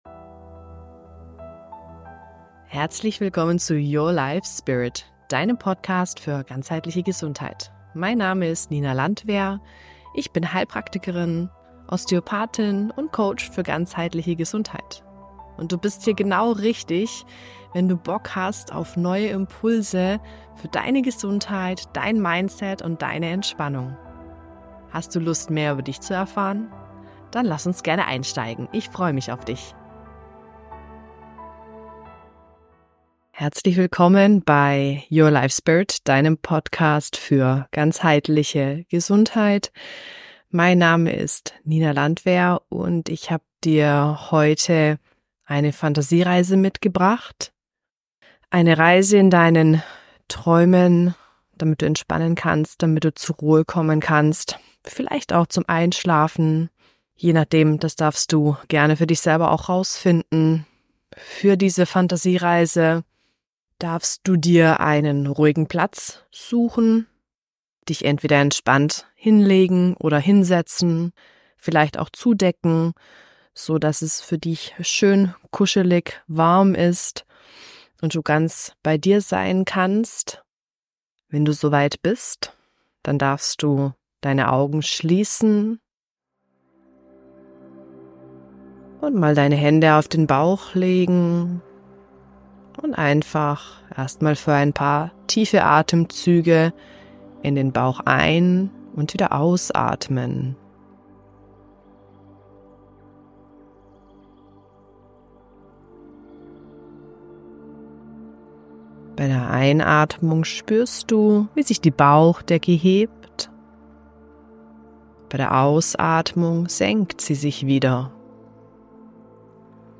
Fantasiereise_final.mp3